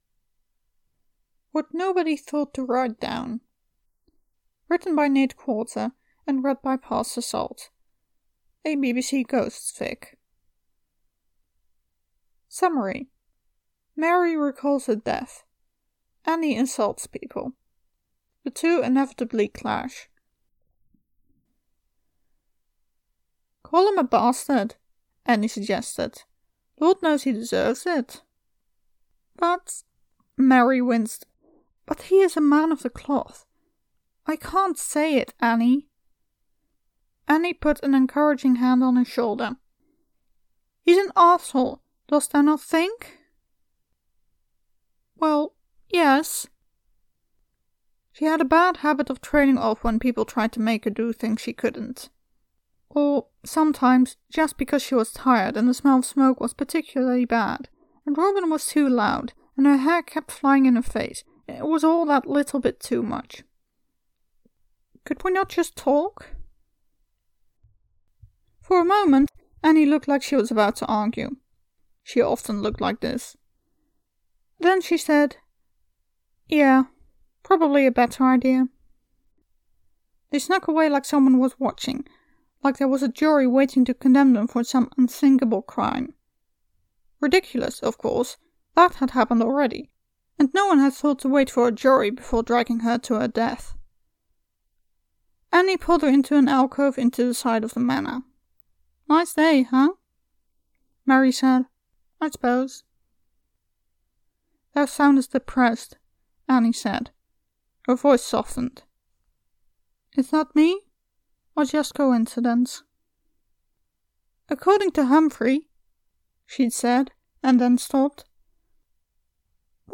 comment to the podficcer here